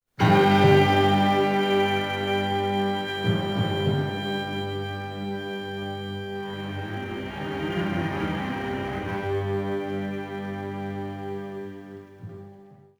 Suspense 2